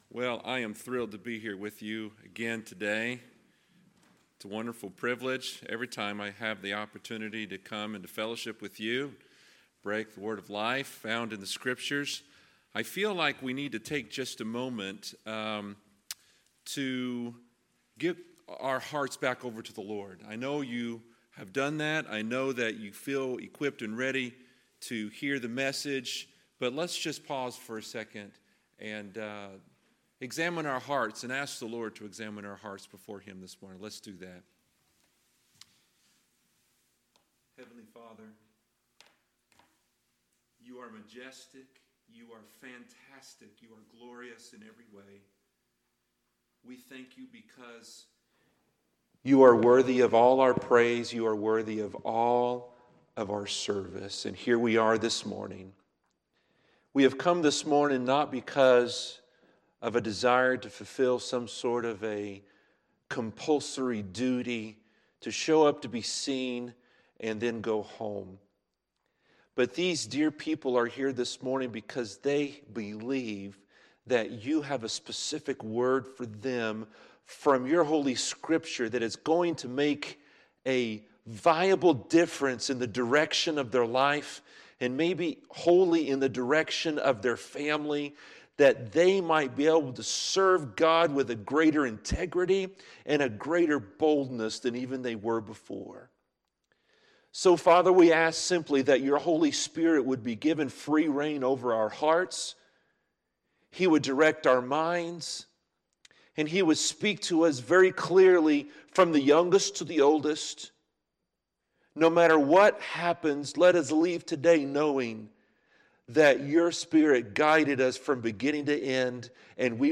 Sunday, July 22, 2018 – Sunday Morning Service